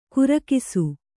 ♪ kurakisu